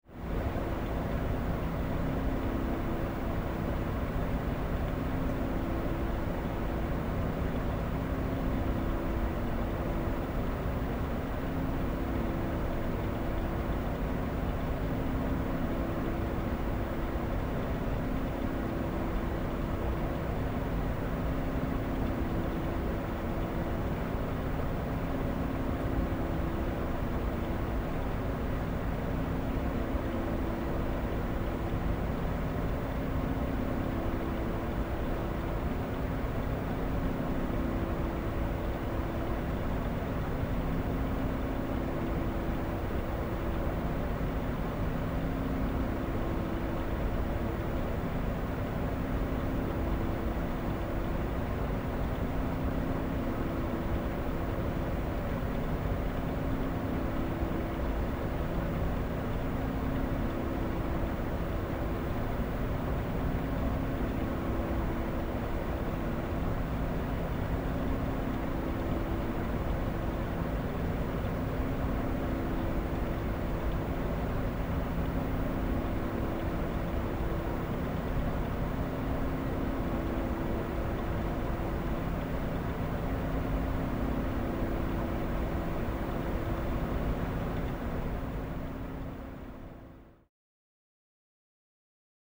Вентиляция в гостинице